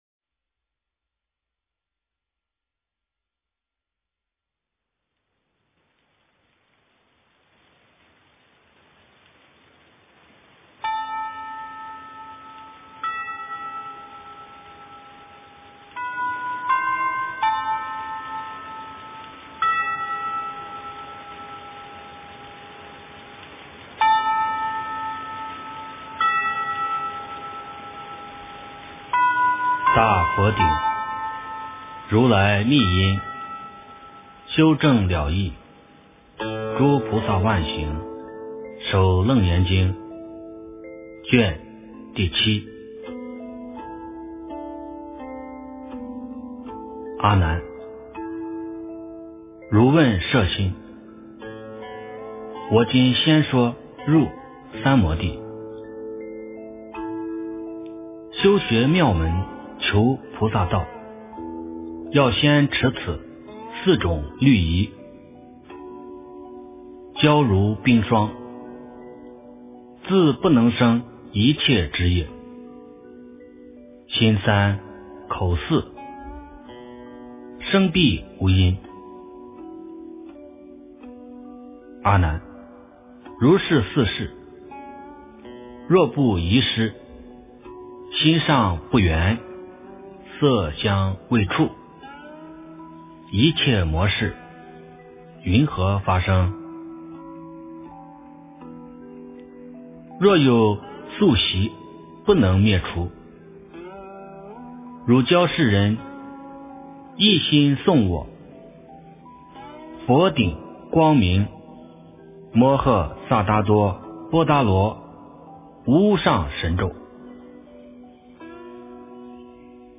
楞严经第07卷 - 诵经 - 云佛论坛